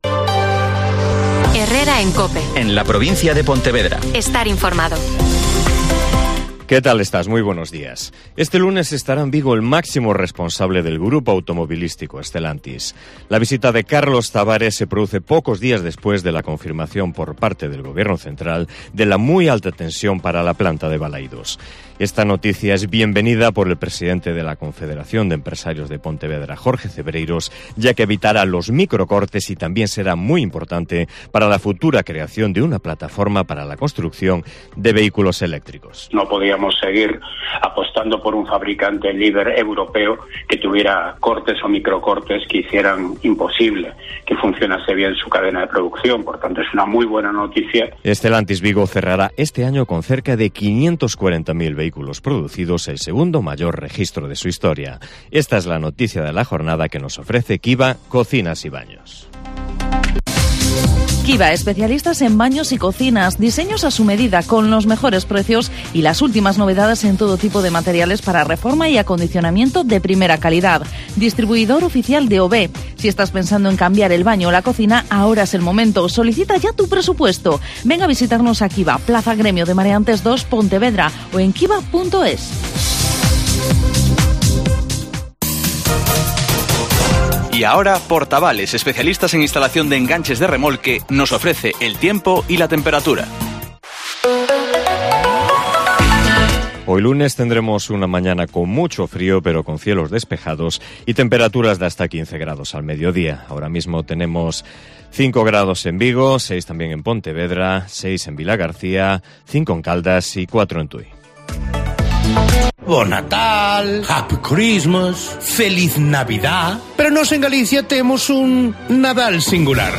Herrera en COPE Pontevedra y COPE Ría de Arosa (informativo 08:24h)